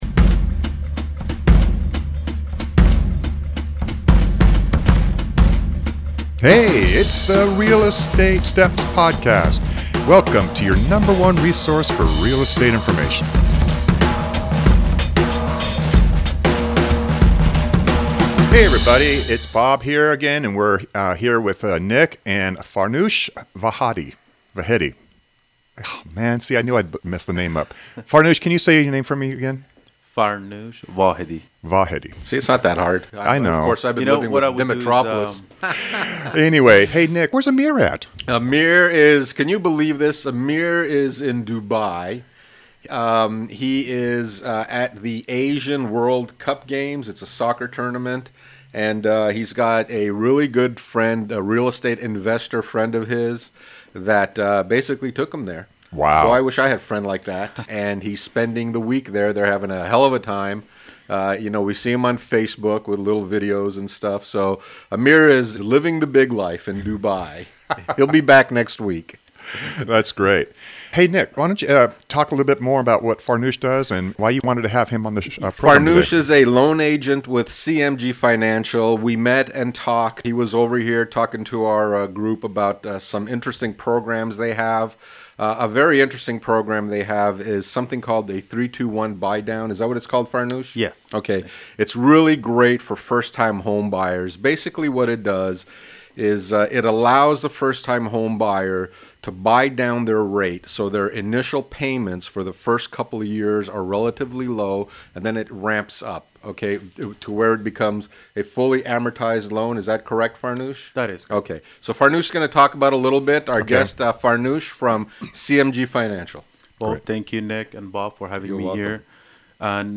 a lively and relevant weekly conversation on what's happening in the Real Estate market in the Sacramento region. It's full of tips, content, and advice for buyers, sellers, and real estate professionals drawn on the years of experience of 3 active Realtors.